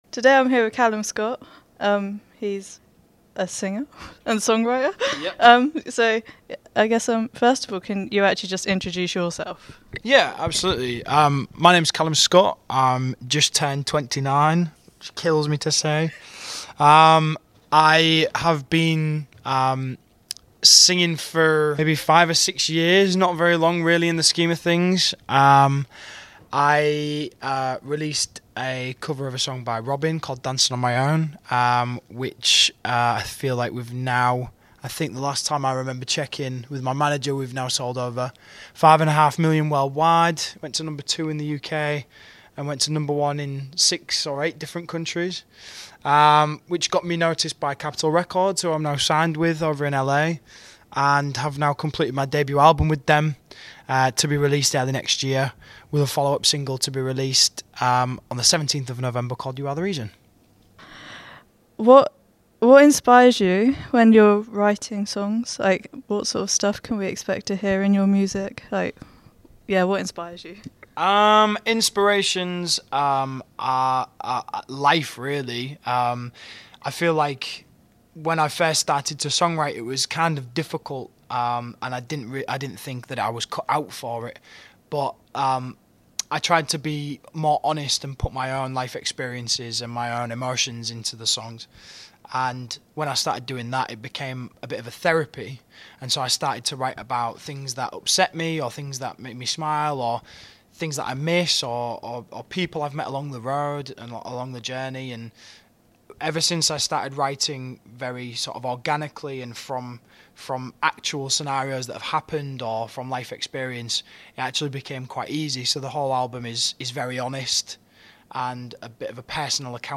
Calum-Scott-interview-mp3.mp3